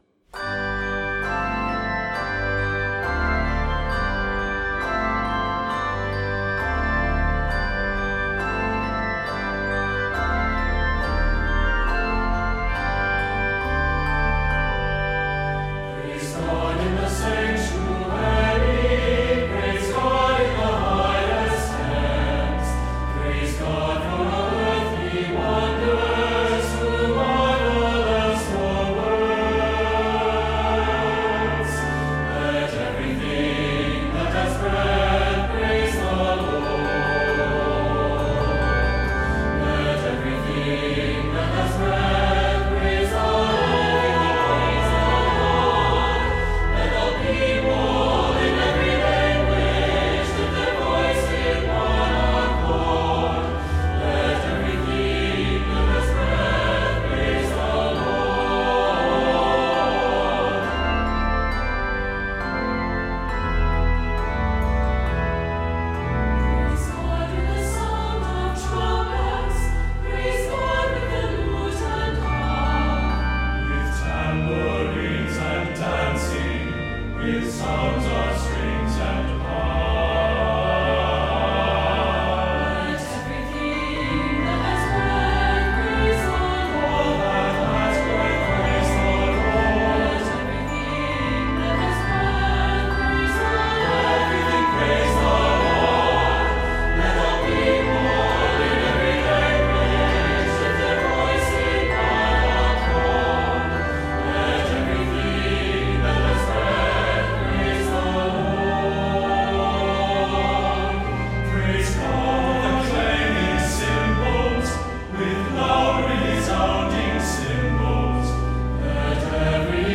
Keys of G Major and Ab Major.